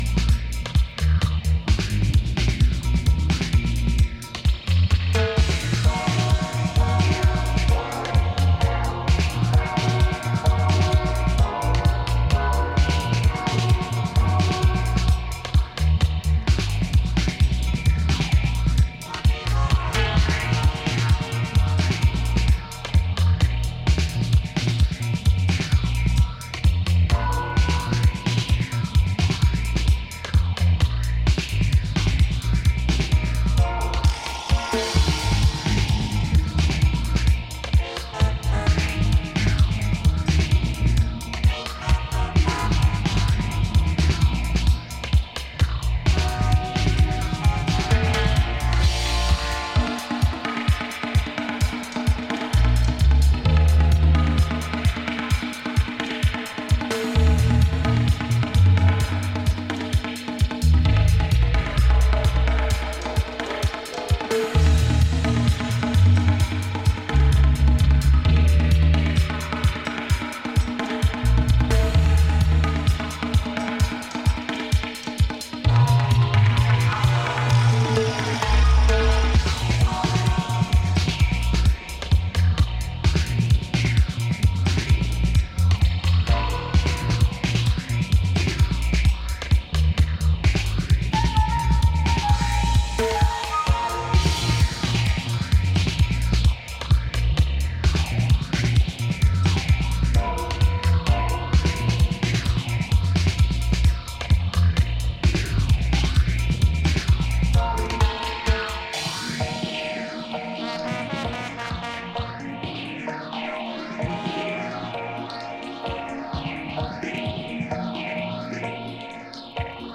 Reggae / Dub